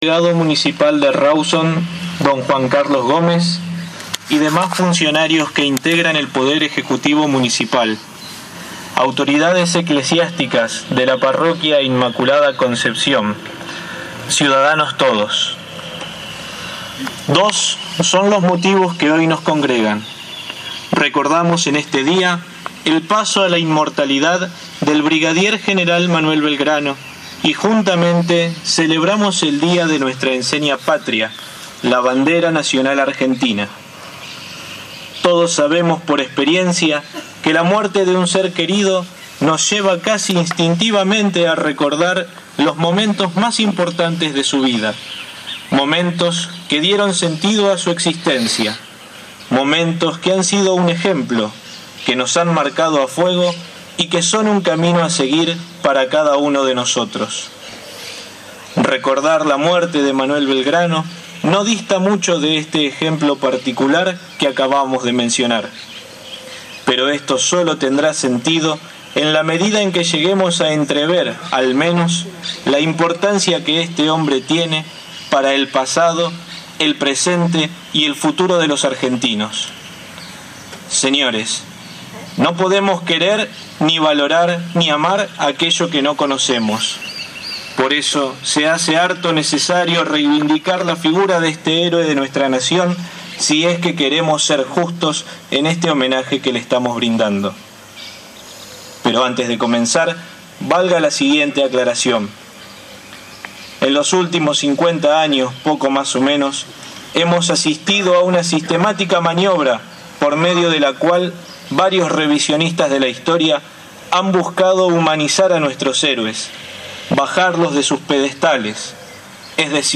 ACTO OFICIAL POR EL DÍA DE LA BANDERA EN RAWSON
Esta mañana, frente al mástil ubicado en plaza General San Martín, se llevó a cabo el acto oficial del Partido de Chacabuco, por el Día de la Bandera.